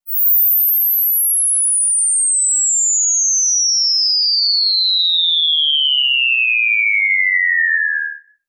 ihob/Assets/Extensions/CartoonGamesSoundEffects/Falling_v1/Falling_v1_wav.wav at master
Falling_v1_wav.wav